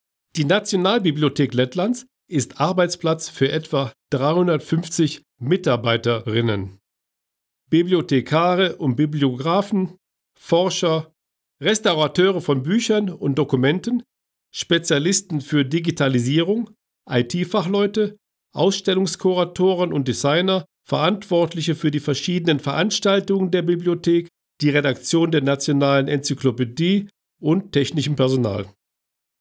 balss aktieris
Tūrisma gidi